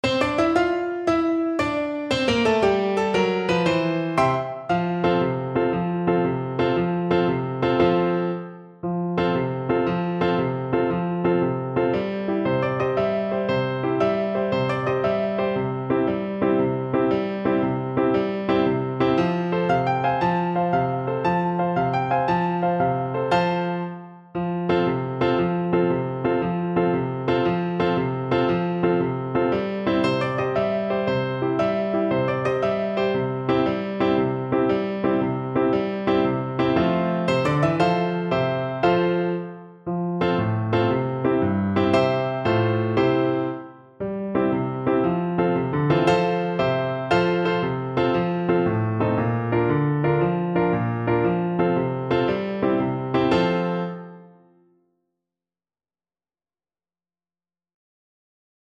6/8 (View more 6/8 Music)
Allegro . = 116 (View more music marked Allegro)
F major (Sounding Pitch) (View more F major Music for Oboe )
Traditional (View more Traditional Oboe Music)